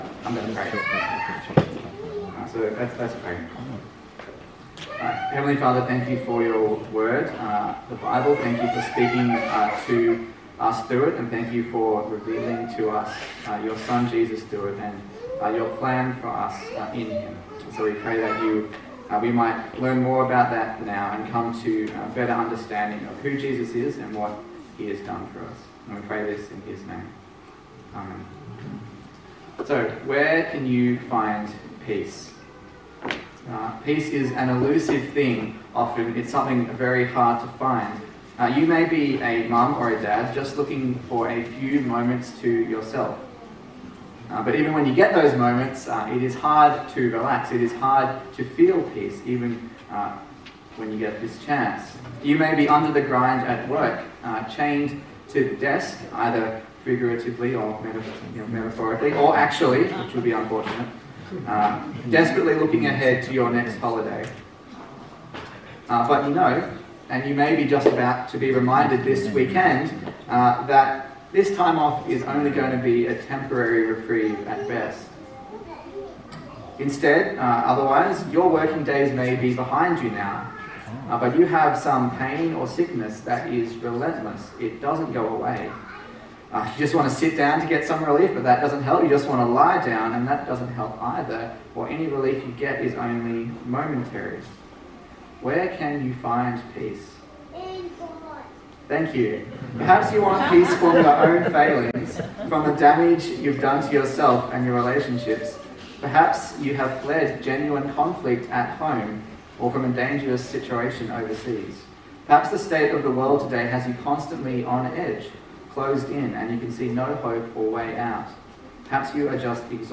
Colossians Passage: Colossians 1:15-21 Service Type: Good Friday